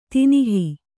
♪ tinihi